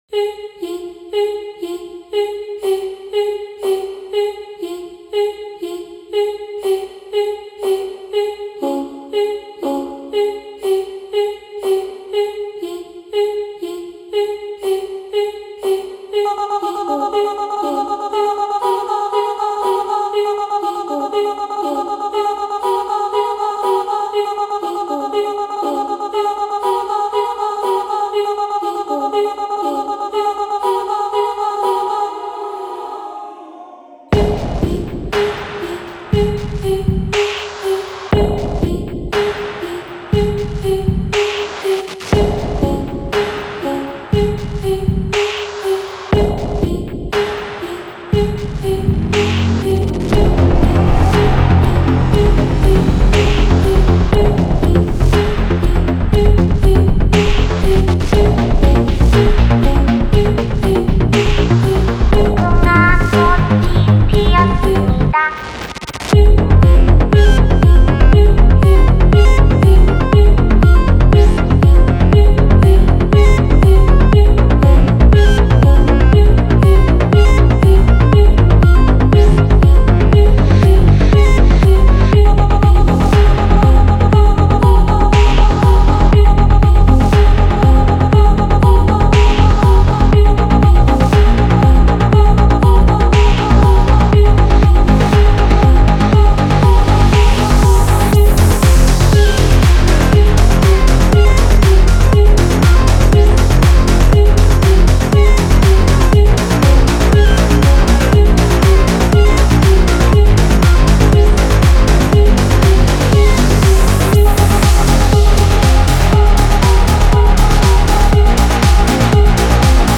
موسیقی متن | ریمیکس آهنگ اصلی